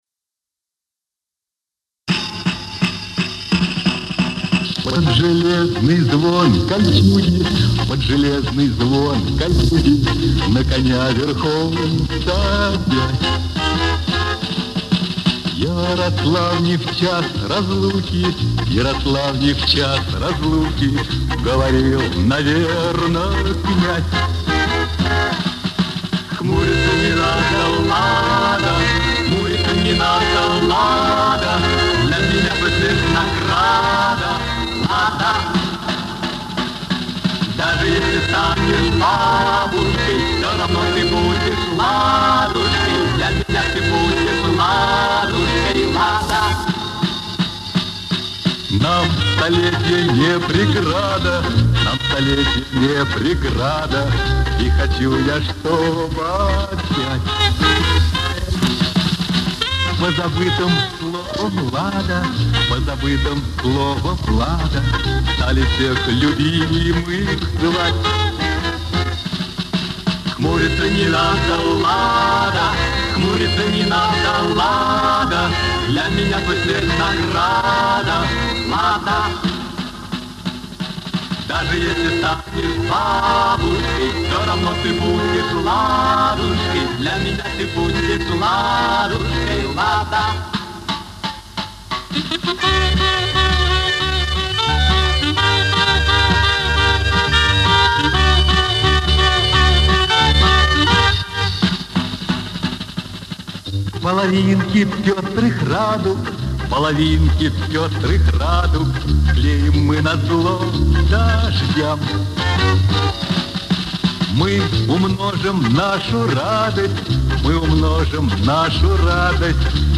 Это запись на магнитной ленте.
Слышен ход магнитной ленты.
Местами слышны замятые участки.